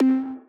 Dist Snare.wav